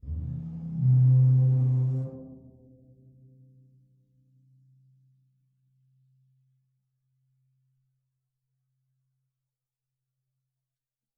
Percussion
bassdrum_rub2_v1.wav